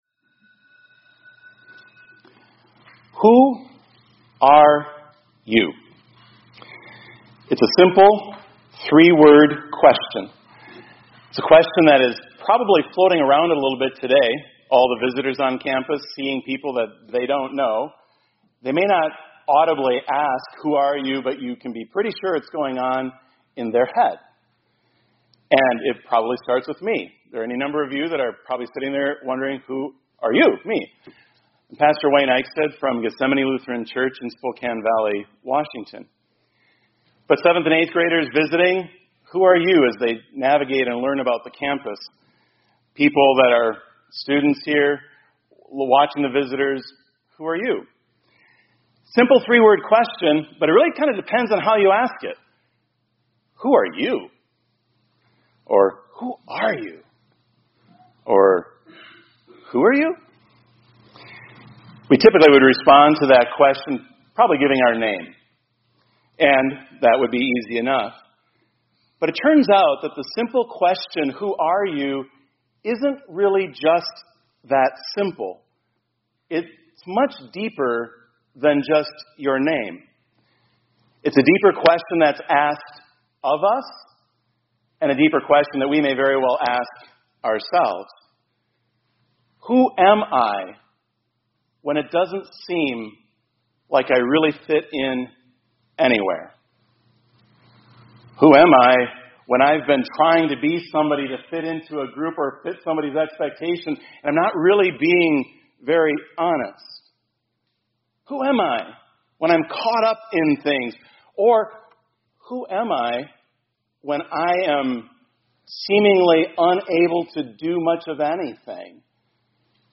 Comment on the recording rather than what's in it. – Immanuel Lutheran High School, College, and Seminary